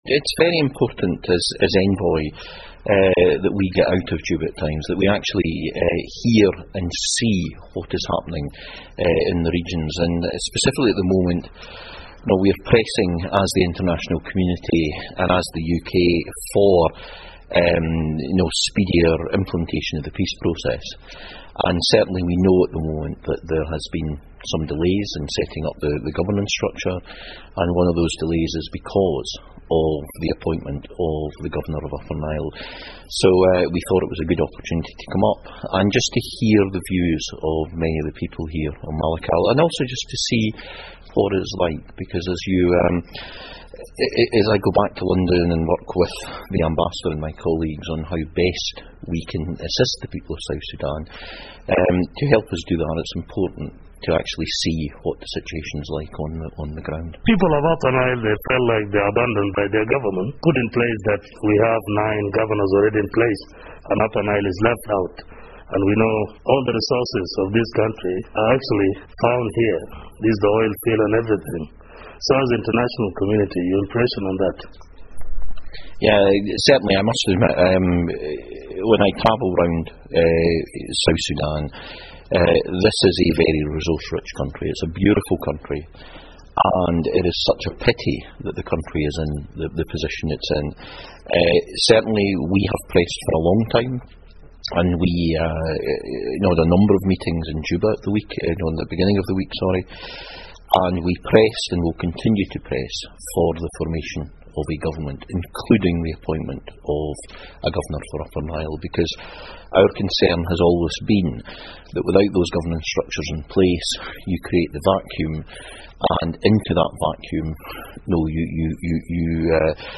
In an exclusive interview with Radio Miraya in Malakal, Special Envoy Fairweather welcomed a planned conference for the communities of Upper Nile to discuss ways and means of resolving tribal or communal differences ahead of the appointment of a state governor.